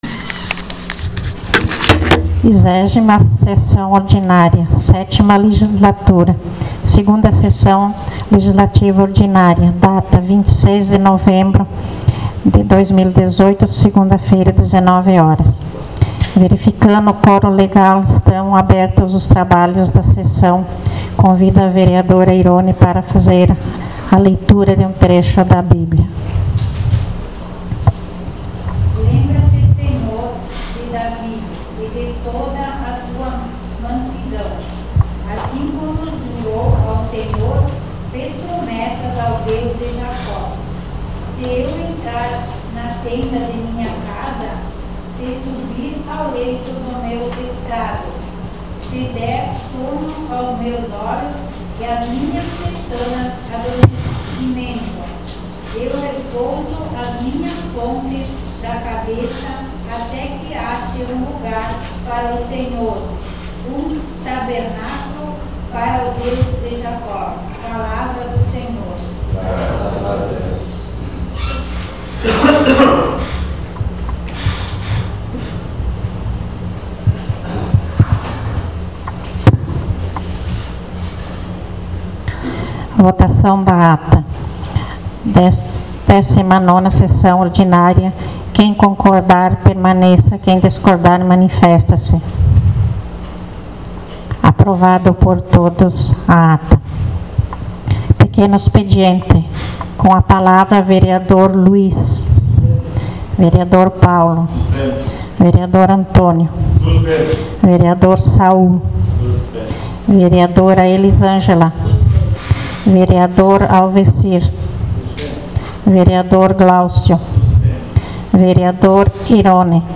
20ª Sessão Ordinária 26.11.18